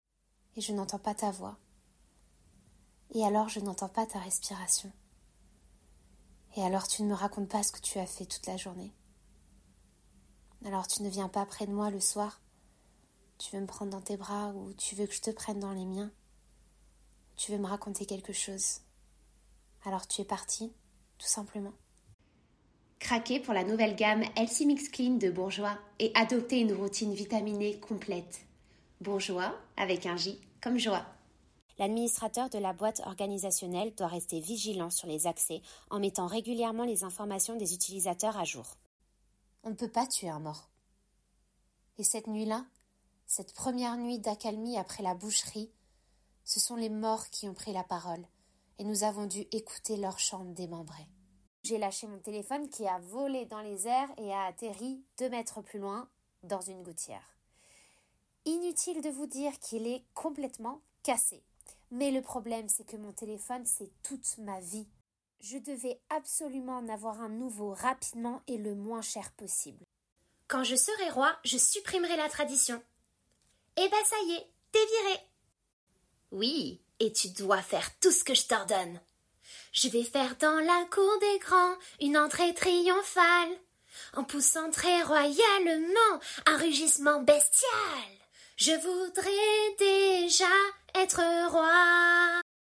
Bande son Français